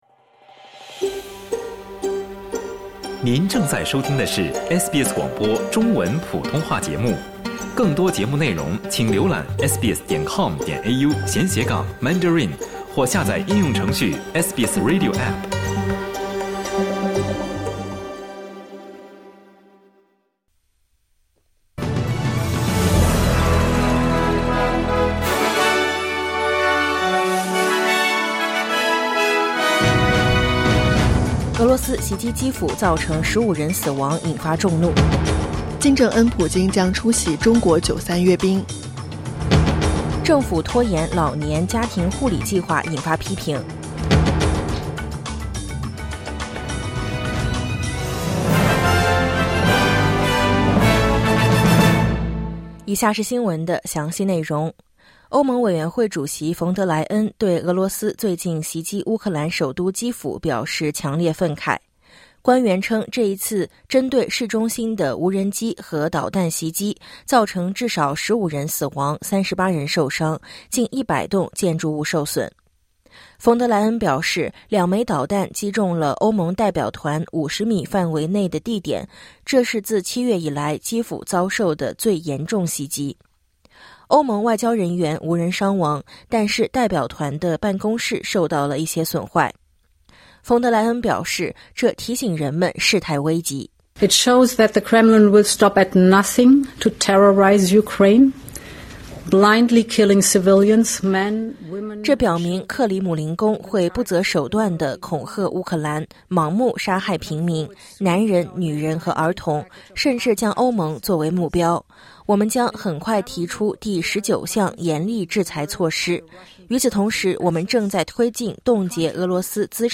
SBS Mandarin morning news Source: Getty / Getty Images